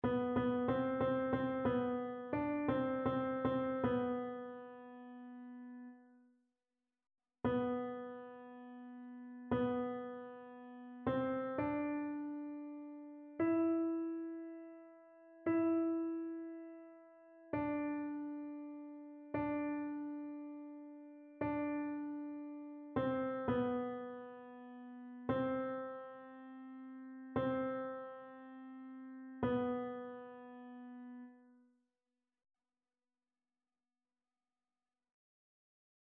Ténor
annee-abc-temps-du-careme-veillee-pascale-psaume-29-tenor.mp3